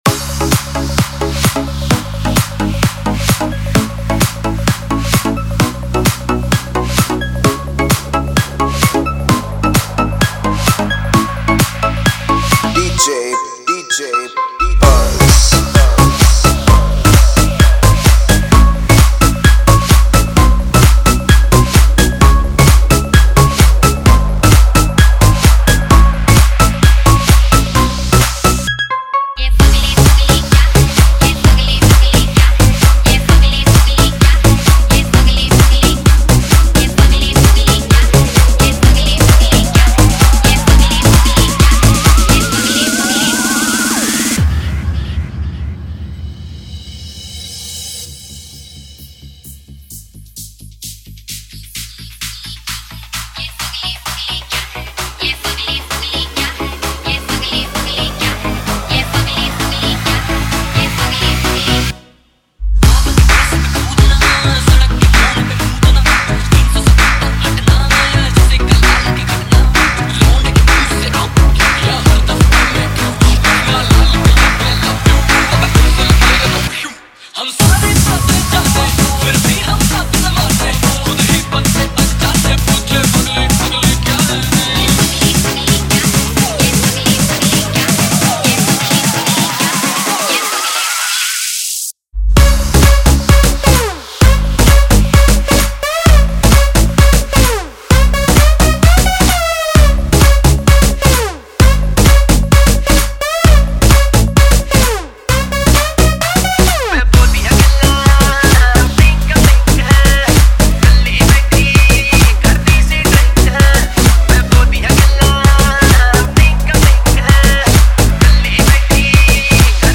Single Dj Mixes